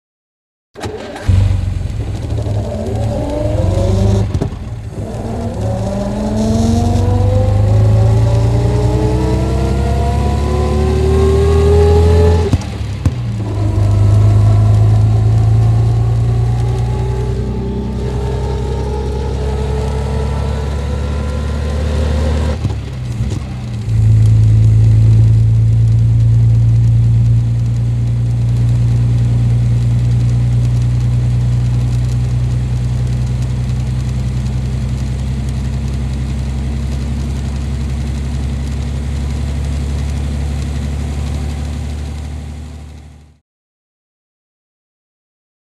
Automobile; Interior; Aston Martin Lagonda Interior Start Up And Away Up Gears.